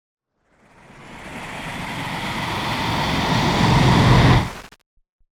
Raging Semi Whoosh